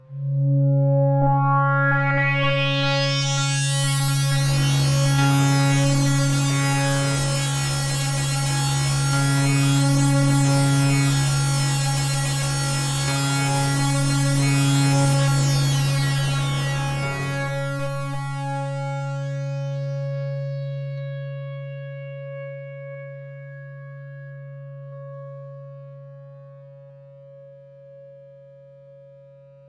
标签： CSharp5 MIDI音符-73 Korg的-Z1 合成器 单票据 多重采样
声道立体声